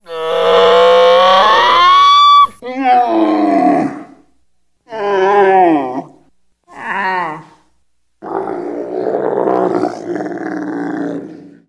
Catégorie Bruitages